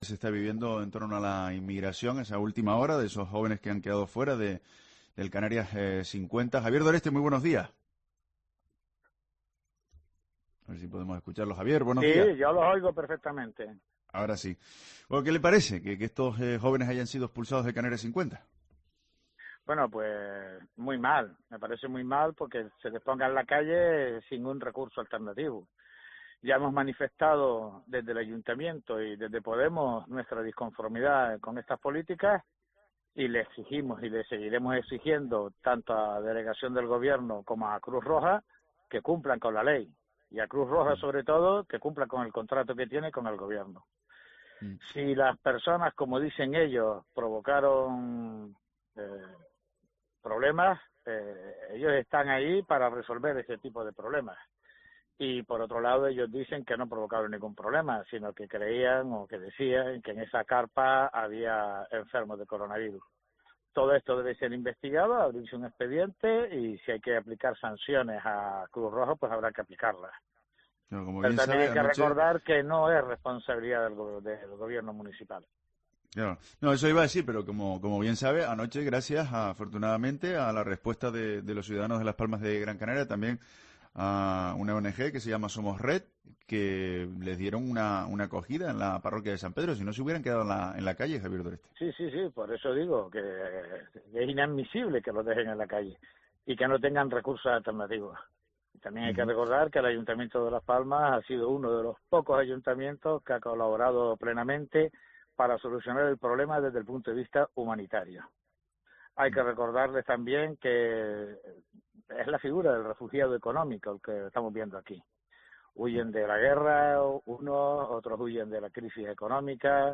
Entrevista a Javier Doreste, concejal de Urbanismo de Las Palmas de Gran Canaria